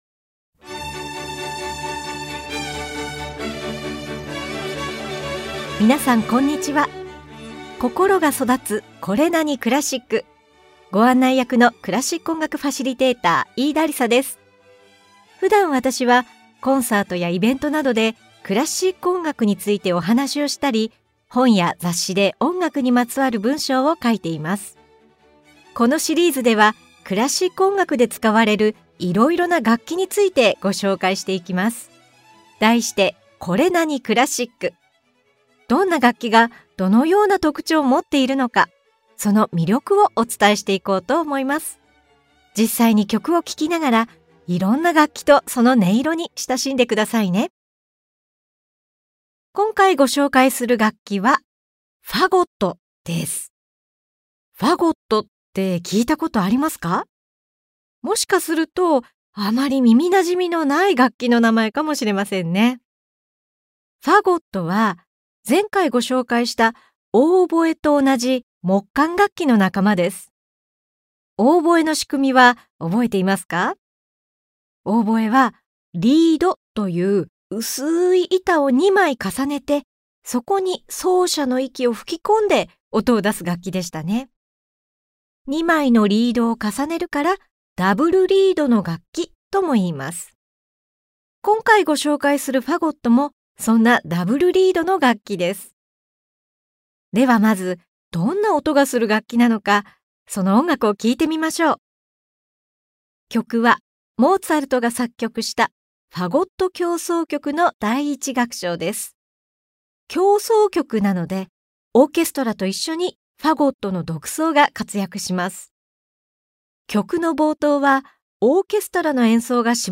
Vol.7では、低い音域で渋く落ち着いた音色のほかユーモラスな表現もできる木管楽器「ファゴット」に注目！オーケストラと一緒にファゴットの独奏が活躍するモーツァルト「ファゴット協奏曲」、ディズニー映画でも使われたポール・デュカが作曲した曲、イタリアの作曲家ヴィヴァルディが作った「ファゴット協奏曲」などを紹介しながら、ファゴットのさまざまな音色とその魅力を紹介します！
[オーディオブック]